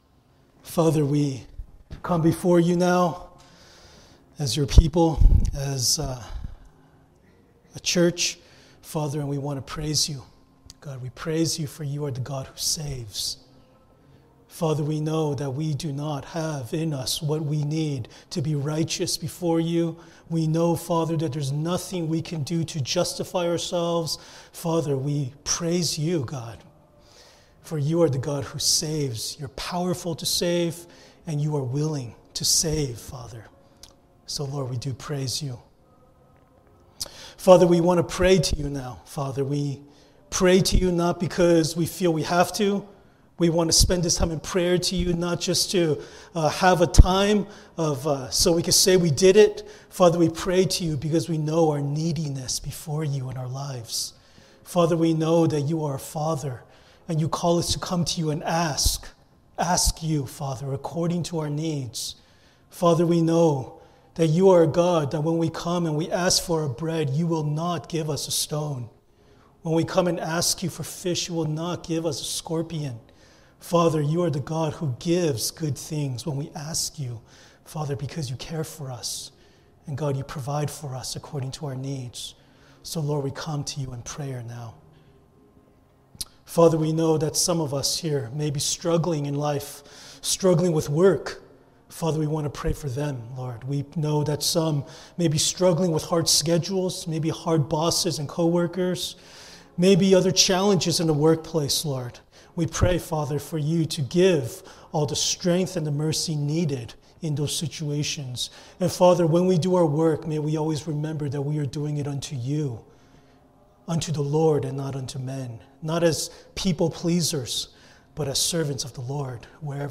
Passage: 1 Corinthians 15:1-2 Service Type: Sunday Worship